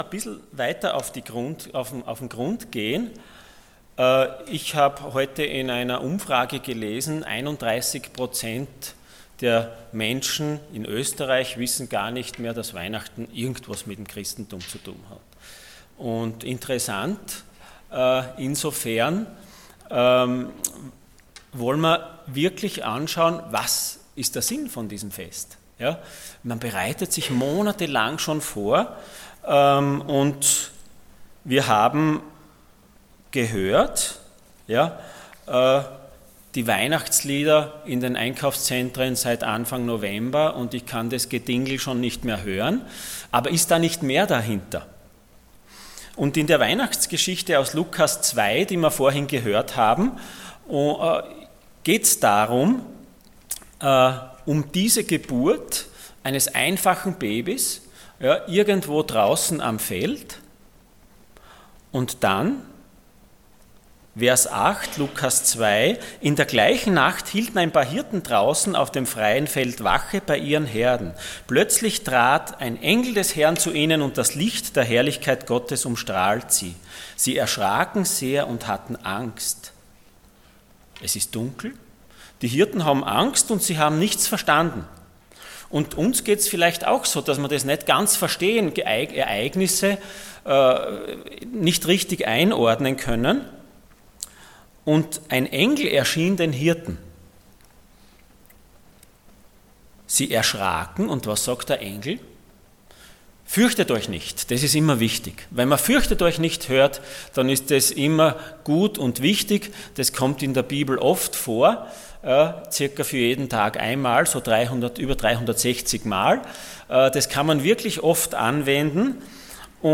Dienstart: Weihnachten